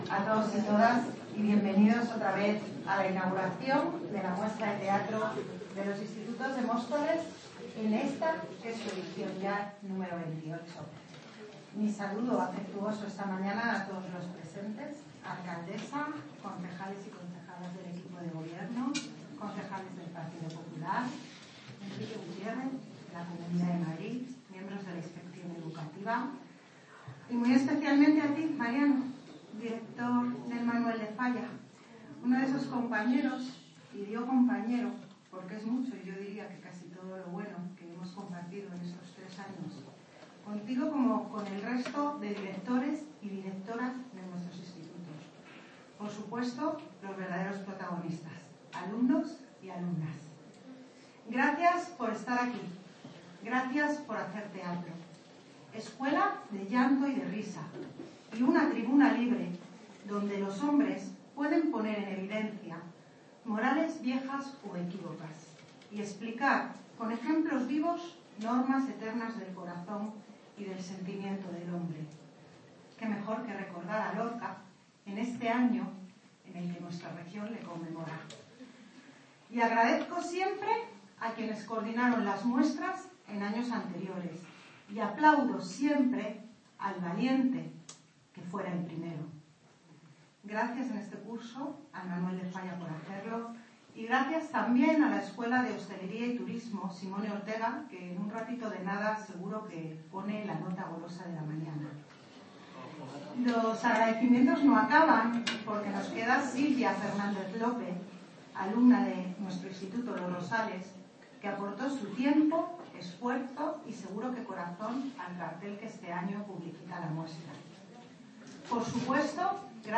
Audio - María Isabel Cruceta (Concejala de Educación) Sobre XXVIII Muestra de Teatro Institutos